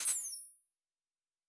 pgs/Assets/Audio/Sci-Fi Sounds/Weapons/Additional Weapon Sounds 4_5.wav at master
Additional Weapon Sounds 4_5.wav